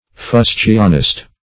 Fustianist \Fus"tian*ist\, n. A writer of fustian.